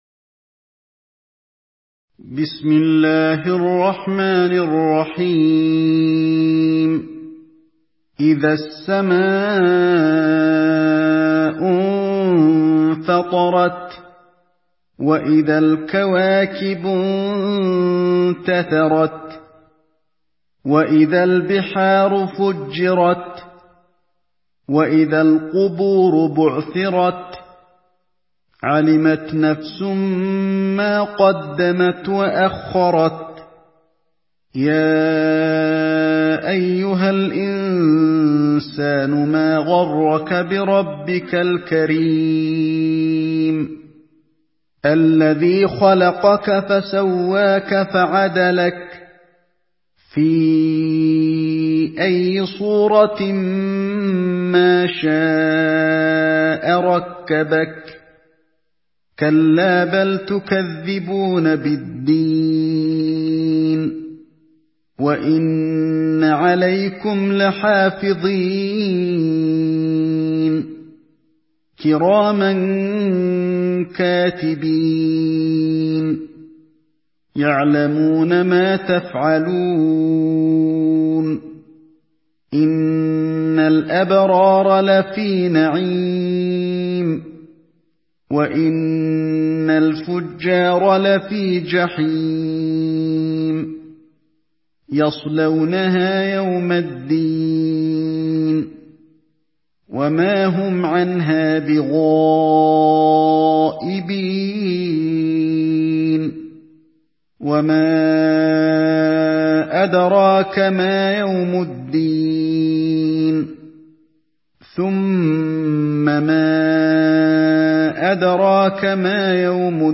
Surah الانفطار MP3 in the Voice of علي الحذيفي in حفص Narration
مرتل